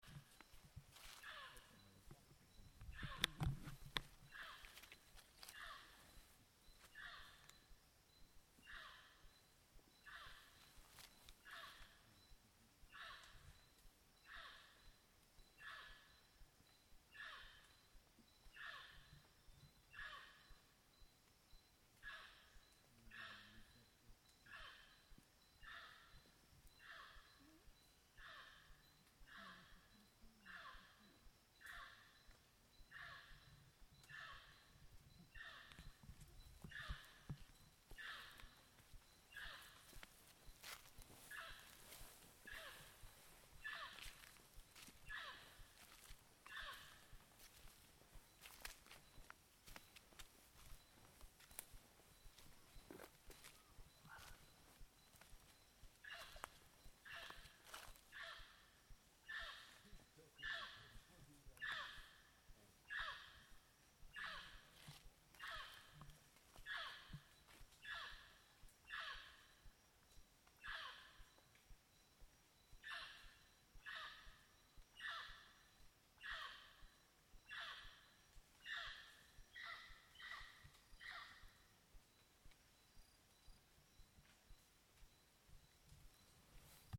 Voici donc un petit reportage sonore de notre périple :
Les oiseaux nous accompagnent de leurs chants variés :